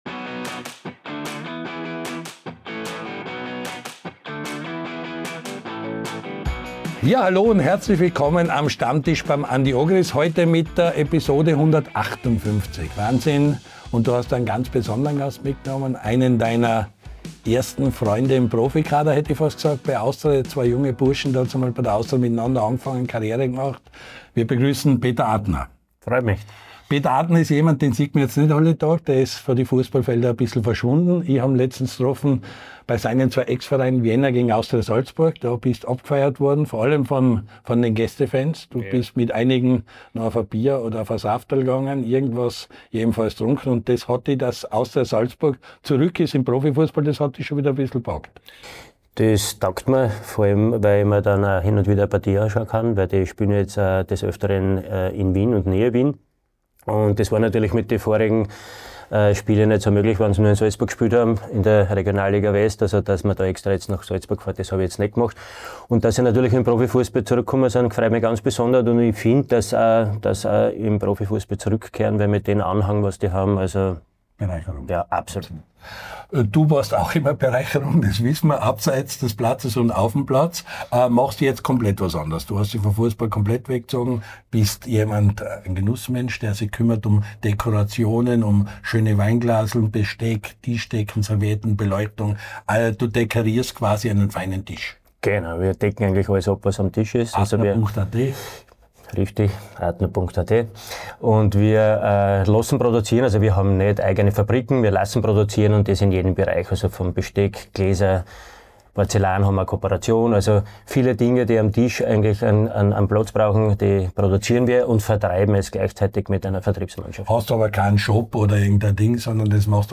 Der LAOLA1-Kult-Talk von und mit Andy Ogris!